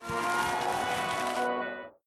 explosion_buildup1.ogg